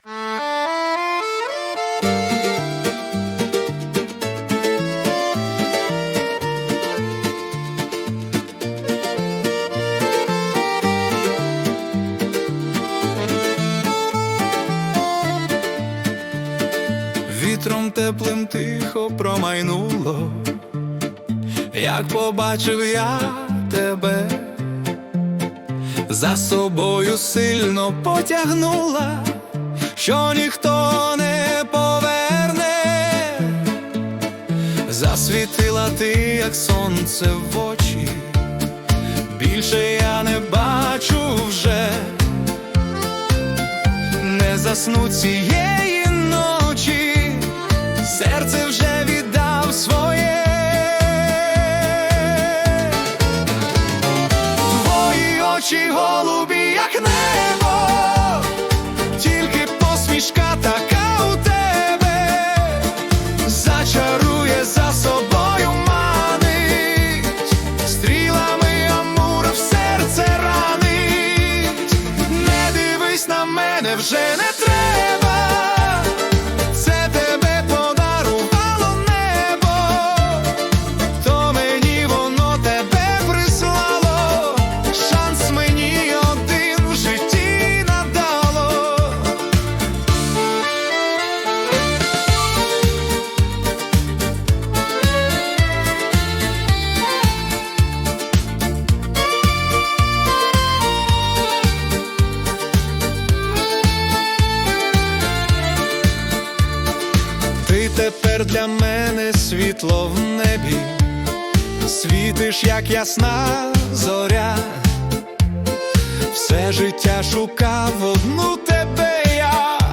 романтична українська пісня про кохання